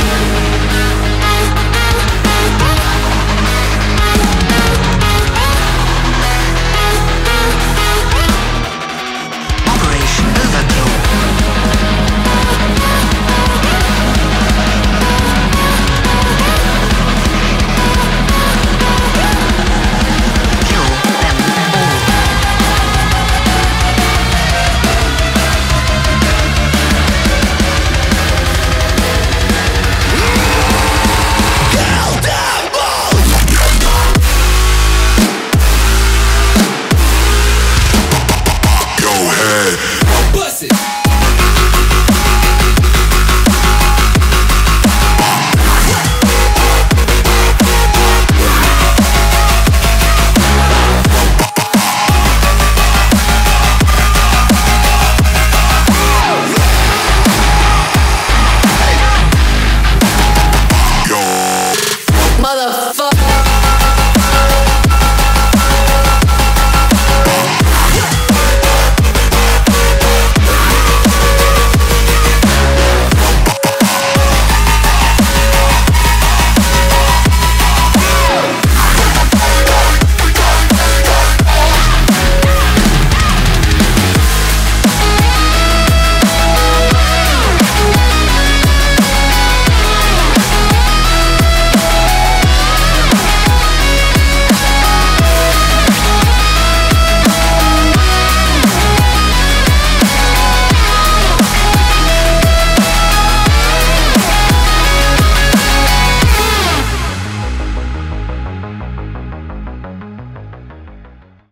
BPM174
Audio QualityMusic Cut